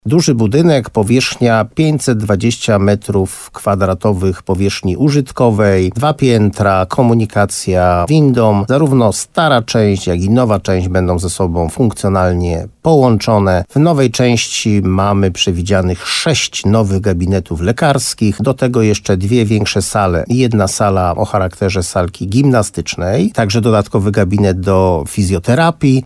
Wójt gminy Moszczenica Jerzy Wałęga, w programie Słowo za Słowo na antenie RDN Nowy Sącz mówił, że pozwolenie na użytkowanie budynku powinno zostać wydane pod koniec kwietnia.